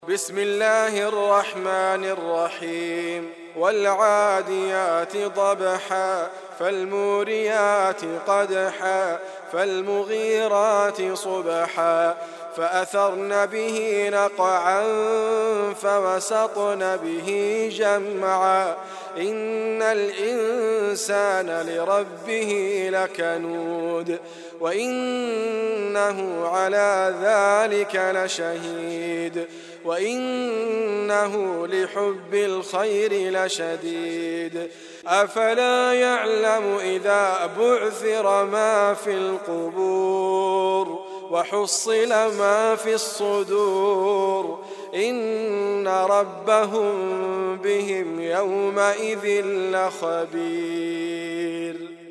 مكتبة محروم الاسلامية - اناشيد , محاضرات , قران كريم mp3 , فيديو , كليبات , محاضرات , ديبيات اسلامية , - العاديات - ادريس ابكر - القران الكريم